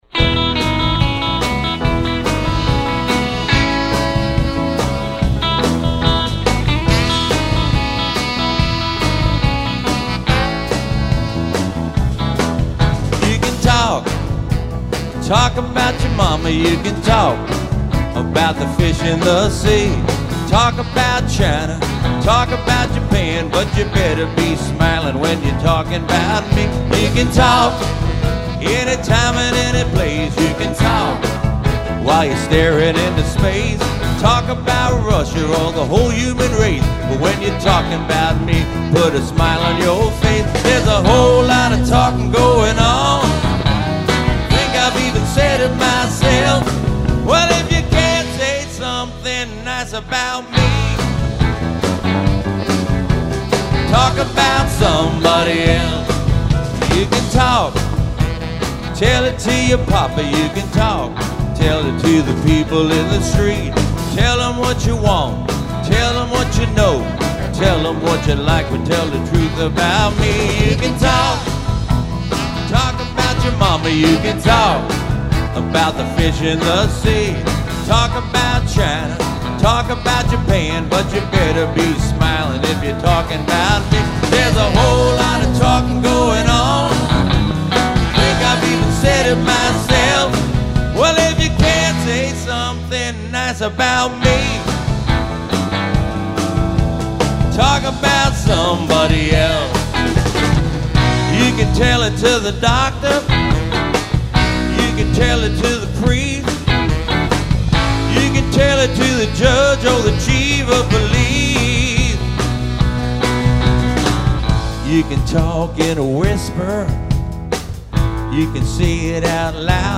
guitar vocals
keys vocals
drums
bass
sax